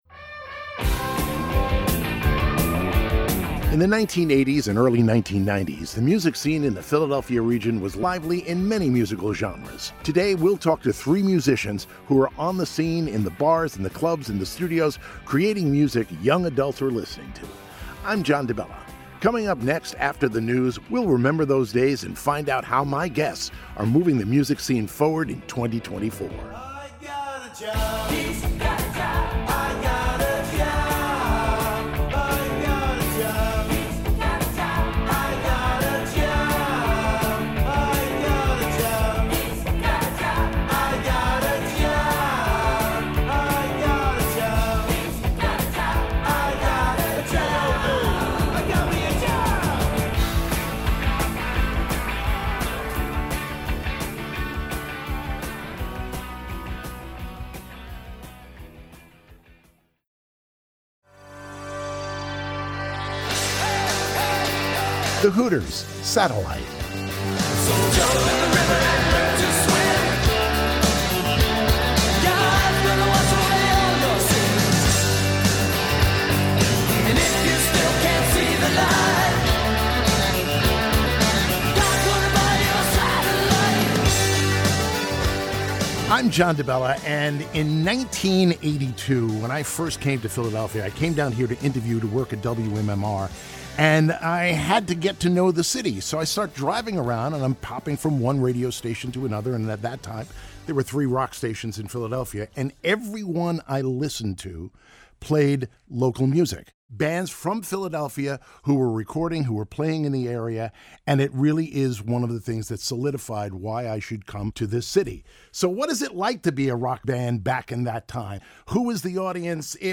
three Philadelphia based musicians taking a look back